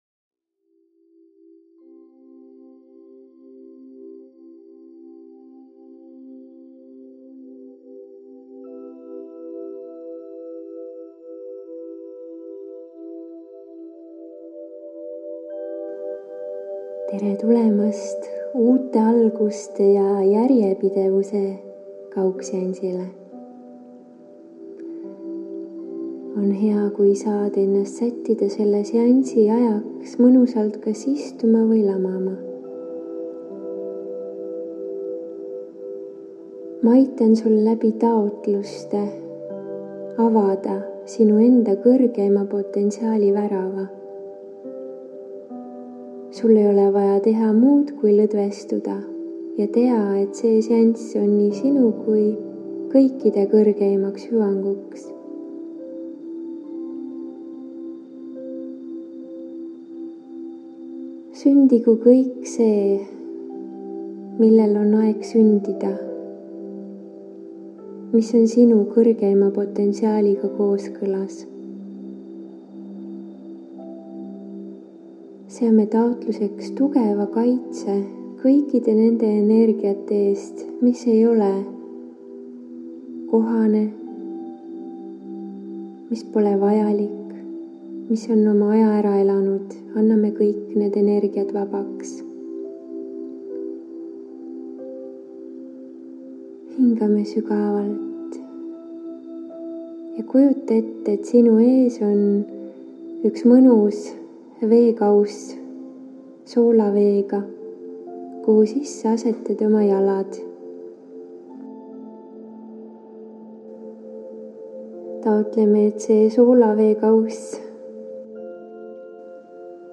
Siit lehelt saad kuulata ja alla laadida järgmist faili: MEDITATSIOON UUED ALGUSED JA JÄRJEPIDEVUS Kingi endale uus reaalsus – selline, kus teed seda, mida armastad ja armastad seda, mida teed, oled tervislik ja terviklik.
Meditatsioon on salvestatud 2018 aastal (nüüd parandatud helikvaliteediga).